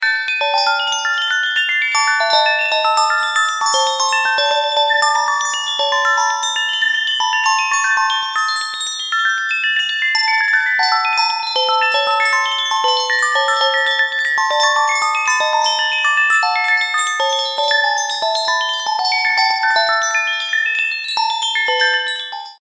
P O L I C E
ghost-in-hotel_14185.mp3